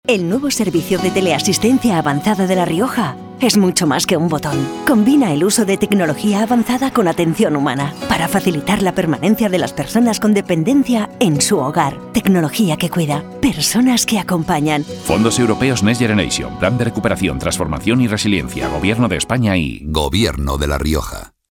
Cuñas radiofónicas
Cuña